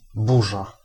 Ääntäminen
Synonyymit tempête tourmente rafale descendante grain squall fortunal Ääntäminen France: IPA: /ɔ.ʁaʒ/ Haettu sana löytyi näillä lähdekielillä: ranska Käännös Ääninäyte Substantiivit 1. burza {f} Suku: m .